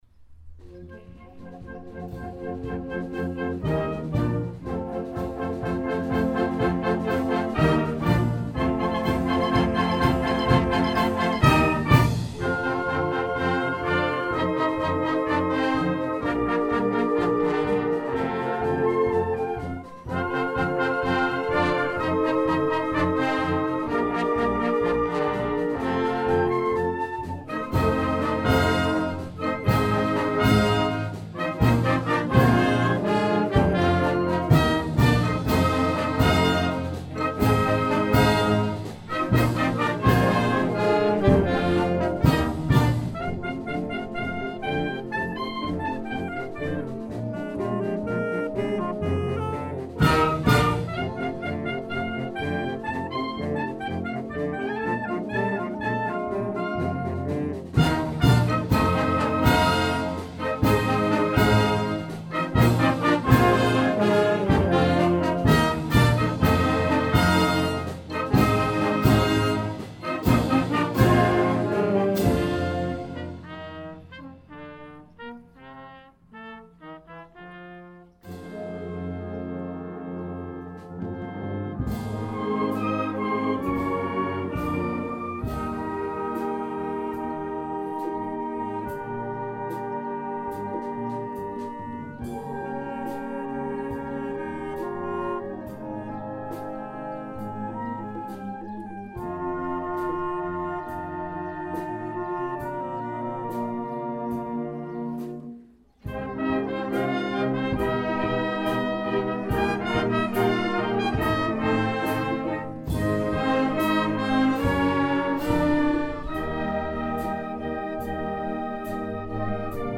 Juntament amb la Banda de Maó